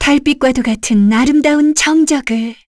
Yuria-Vox_Skill3_kr.wav